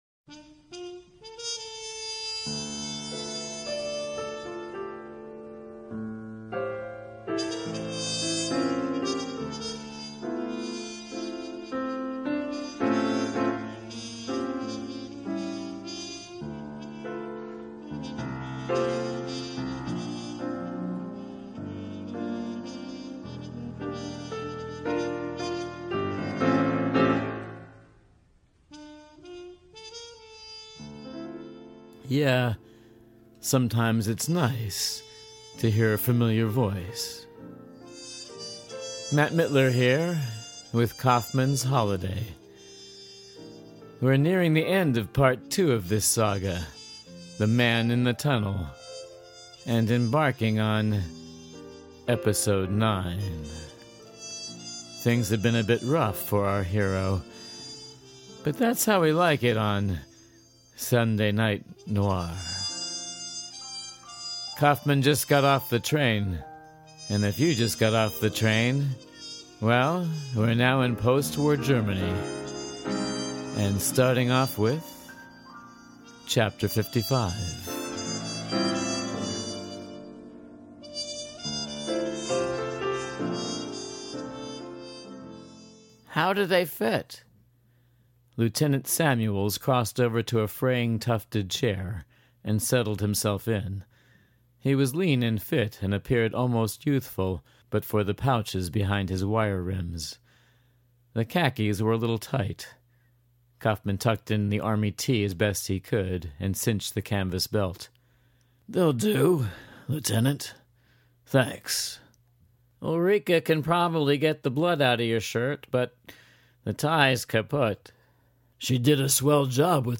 I wrote this book, and I’m reading from it.